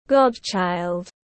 Con đỡ đầu tiếng anh gọi là godchild, phiên âm tiếng anh đọc là /ˈɡɒd.tʃaɪld/.
godchild.mp3